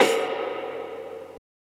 Godzilla Perc 6.wav